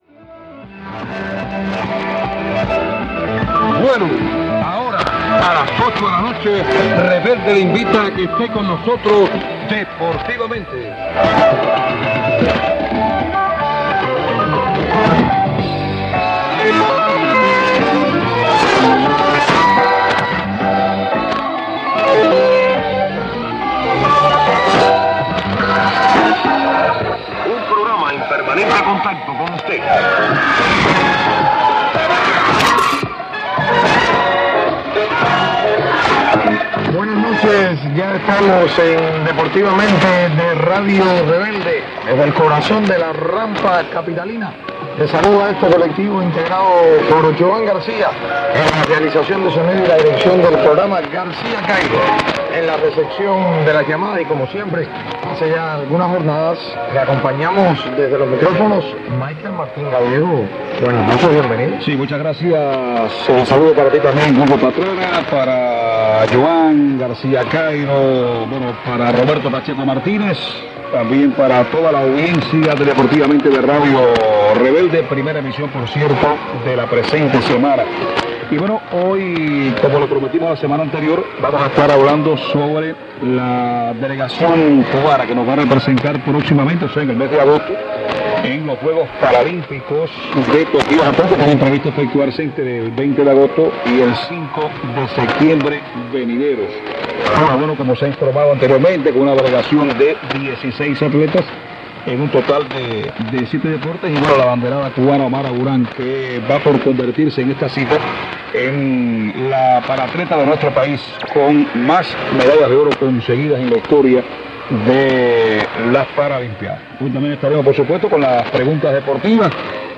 Registro de la estación de Radio Rebelde que transmite en los 5025 khz.
Receptor: Kenwood R.600 Antena: Hilo largo (10 metros aprox).